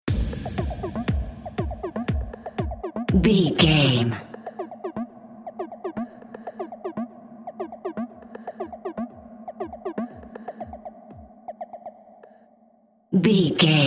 Top 40 Chart Electronic Dance Music Stinger.
royalty free music
Atonal
SEAMLESS LOOPING?
groovy
dreamy
smooth
futuristic
drum machine
synthesiser
house
techno
trance
synth leads
synth bass
upbeat
uptempo